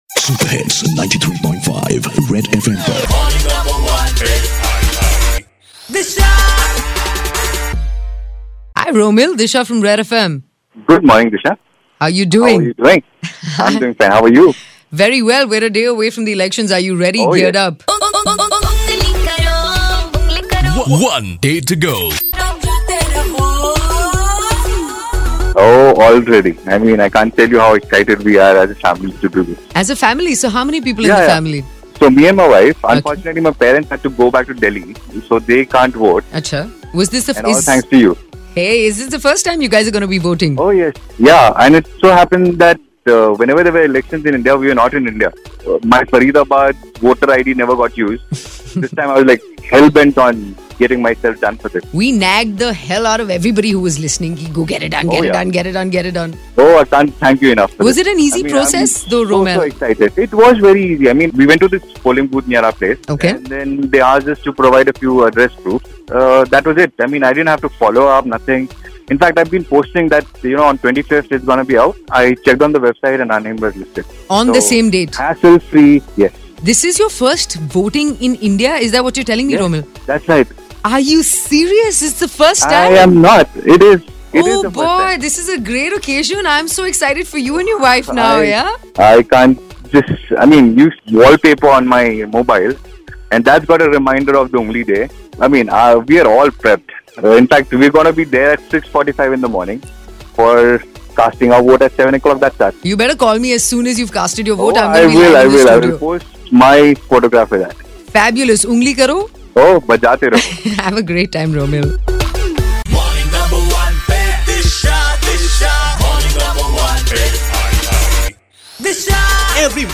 A listener who got done his Voter ID sharing his excitement to vote